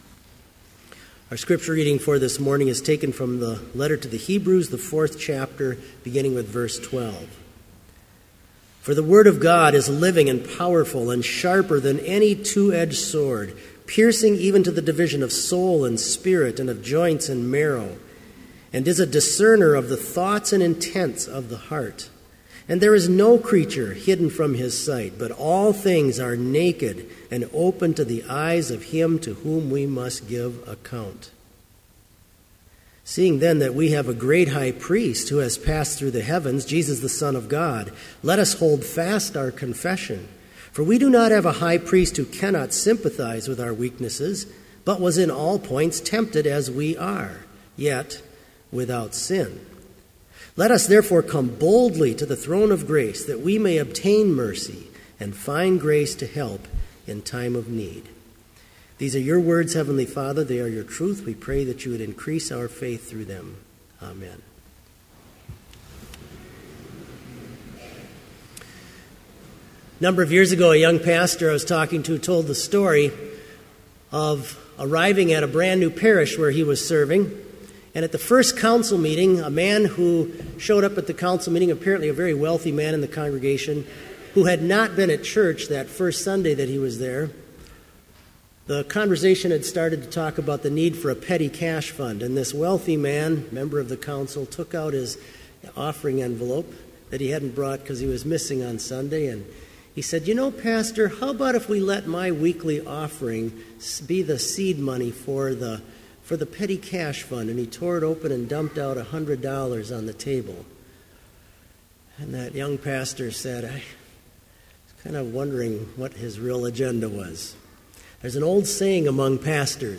Complete service audio for Chapel - April 7, 2014
Prelude Hymn 289, vv. 1-4, Join All the Glorious Names Reading: Hebrews 4:12-16 Homily Prayer Hymn 289, vv. 5-7, Jesus, my great … Benediction Postlude